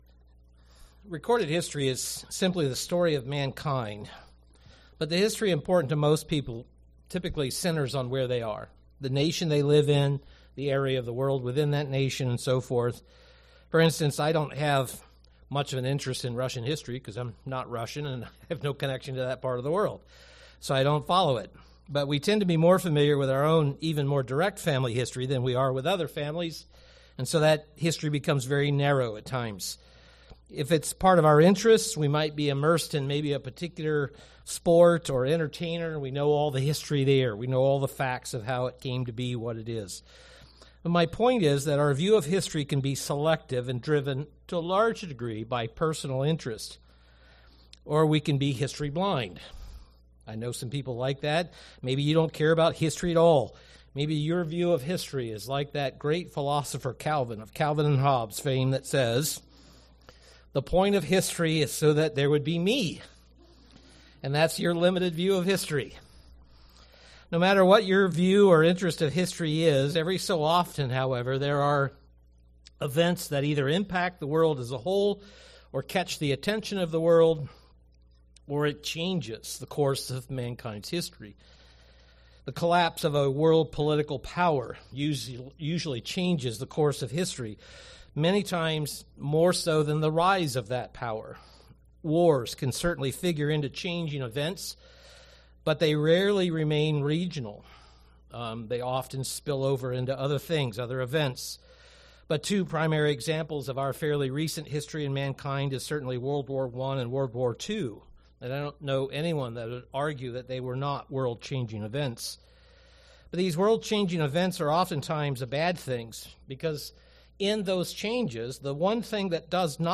Given in Milwaukee, WI